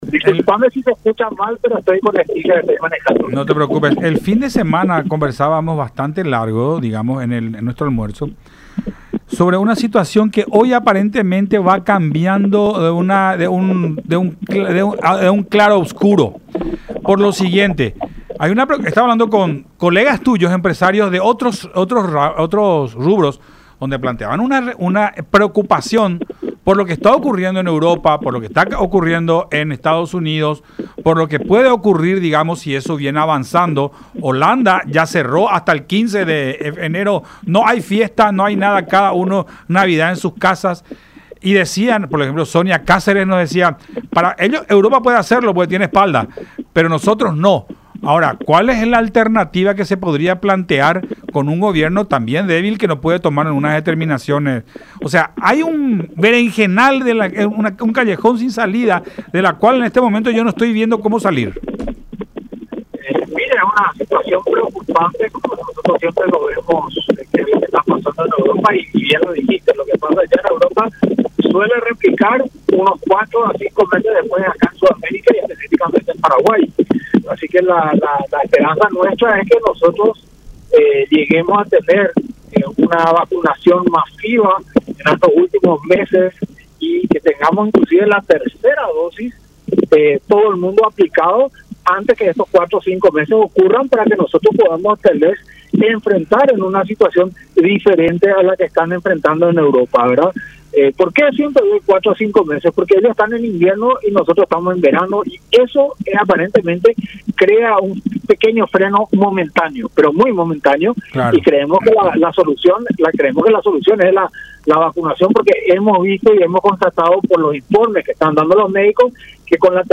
“Yo quiero saber si los no vacunados van a venir a pagar mi cuenta de luz porque nos vuelven a cerrar los restaurantes”, criticó en contacto con Enfoque 800 a través de La Unión.